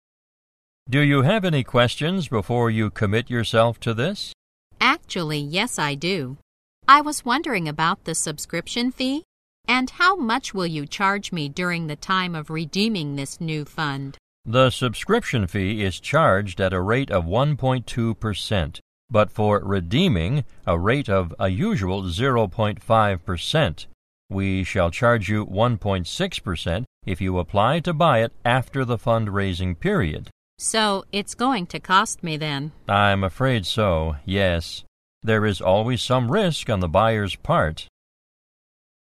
在线英语听力室银行英语情景口语 第88期:代理服务 代理基金业务(4)的听力文件下载, 《银行英语情景口语对话》,主要内容有银行英语情景口语对话、银行英语口语、银行英语词汇等内容。